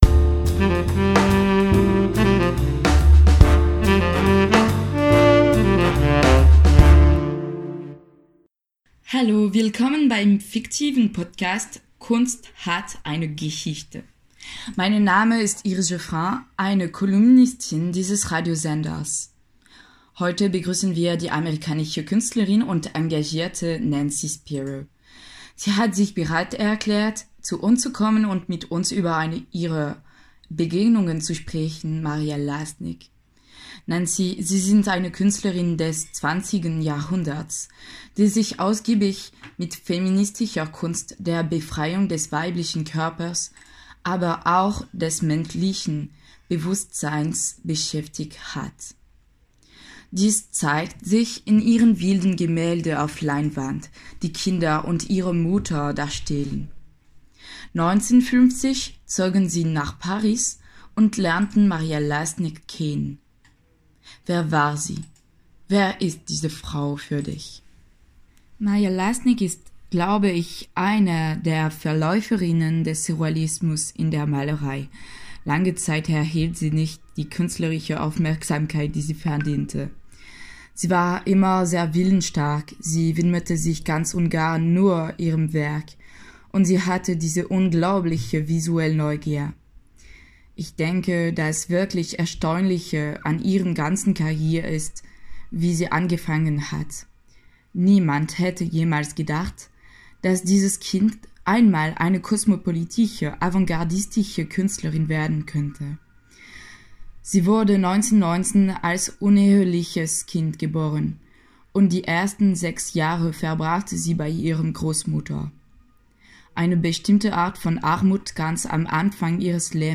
Sie hören den Podcast „Kunst hat eine Geschichte“ mit Nancy Spero als Gast, die über Maria Lassnig spricht.